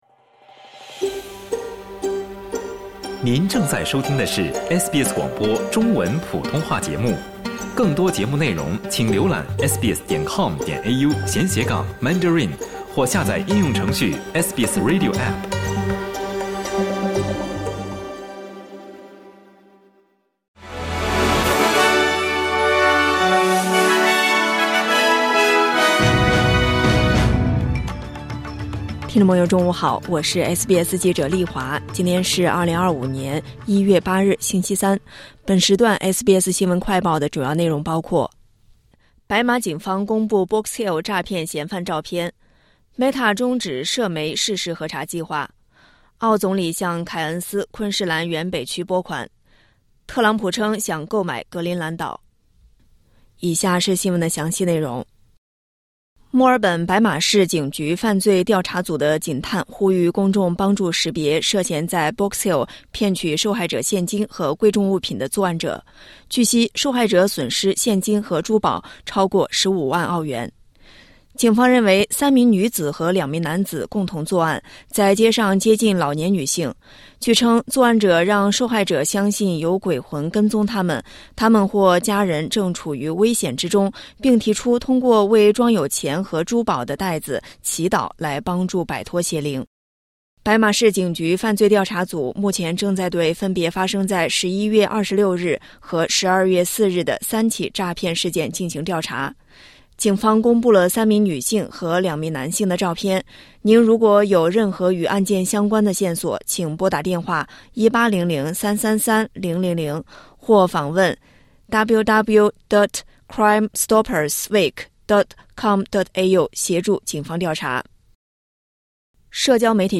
【SBS新闻快报】白马警方公布Box Hill诈骗嫌犯照片